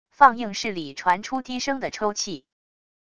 放映室里传出低声的抽泣wav音频